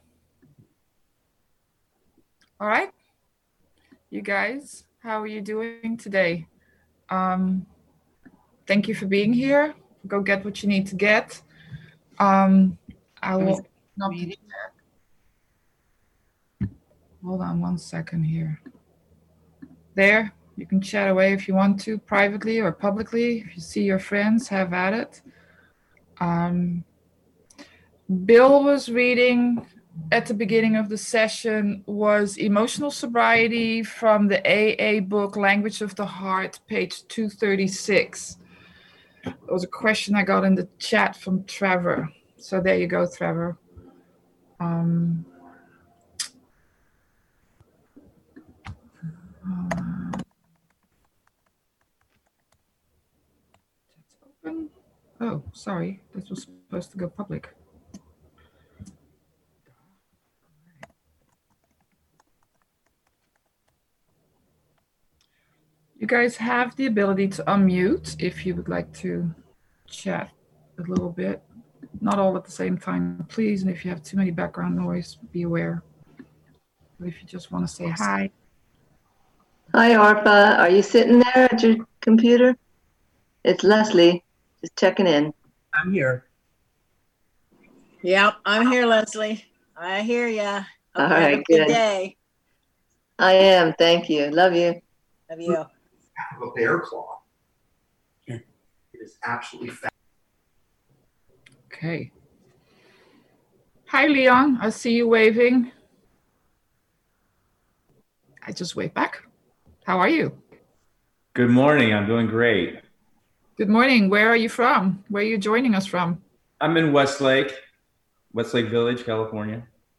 AWB Workshop - Emotional Sobriety